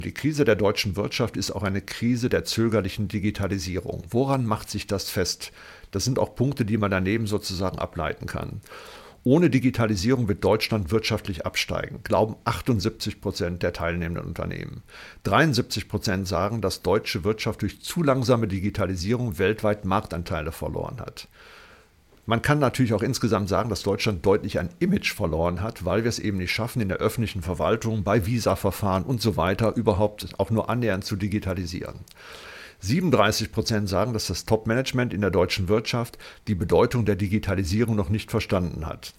Mitschnitte der Pressekonferenz
pressekonferenz-digitalisierung-der-wirtschaft-2025-krise-der_zoegerlichen-digitalisierung.mp3